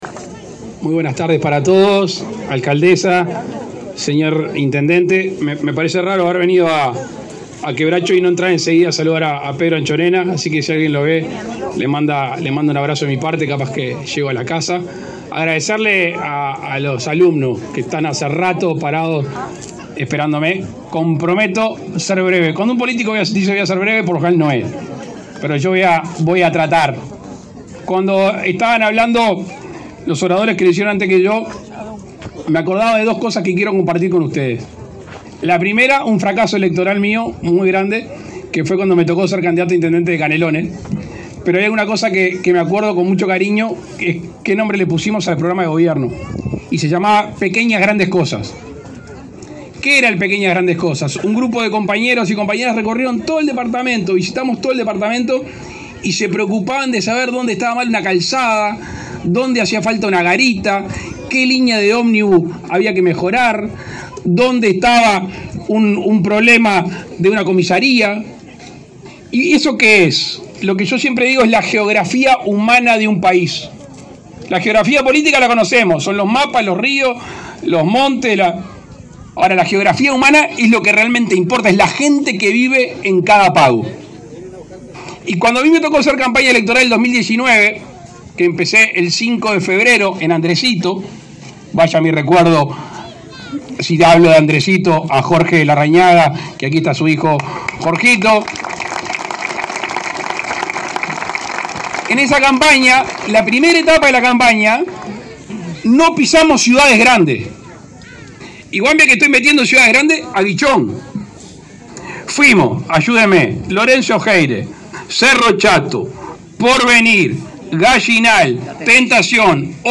Palabras del presidente Luis Lacalle Pou en Quebracho
El presidente Luis Lacalle Pou encabezó en Paysandú el acto de inauguración de la rotonda de ingreso a la localidad de Quebracho.